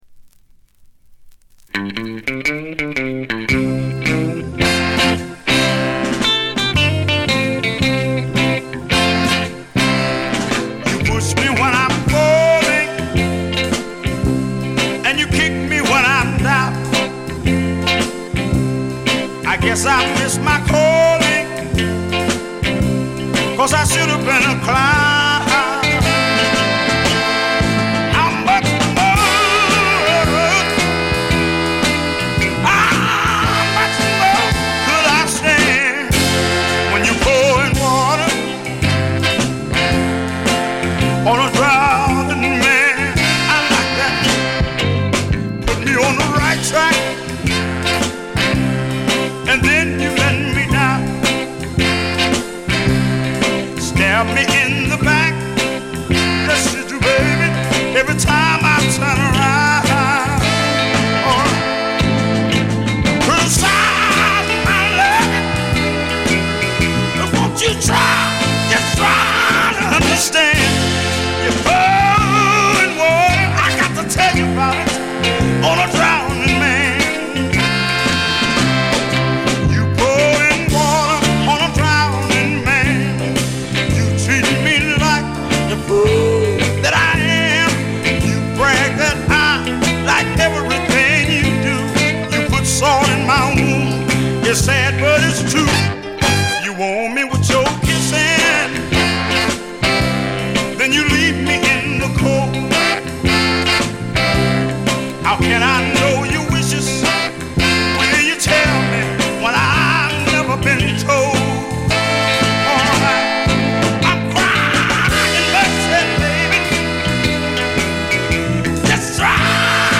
モノラル・プレス。
試聴曲は現品からの取り込み音源です。